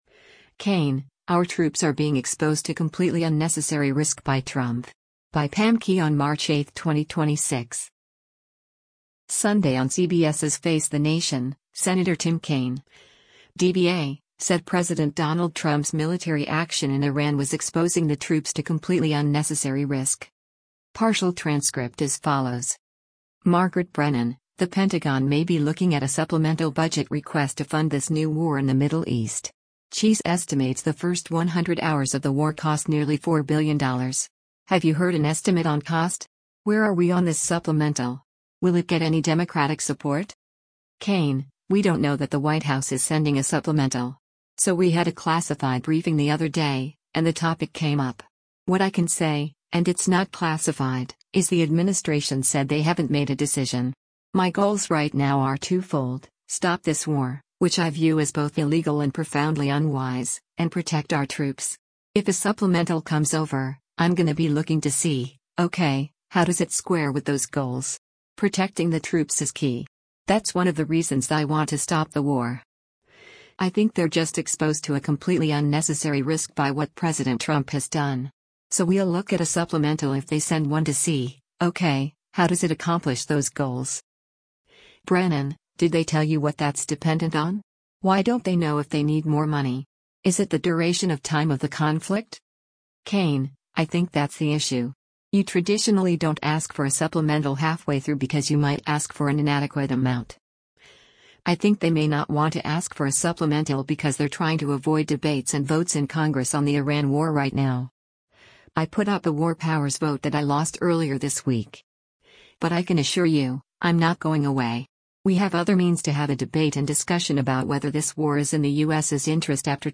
Sunday on CBS’s “Face The Nation,” Sen. Tim Kaine (D-VA) said President Donald Trump’s military action in Iran was exposing the troops to “completely unnecessary risk.”